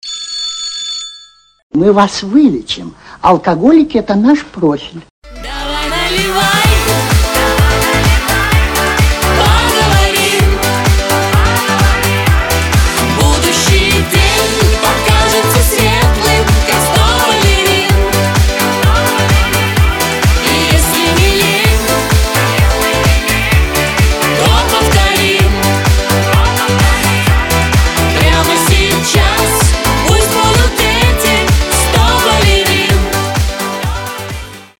• Качество: 320, Stereo
поп
позитивные
веселые
Club House
пьяные
застольные
смешные
Веселая застольная песня с фразой "Мы вас вылечим!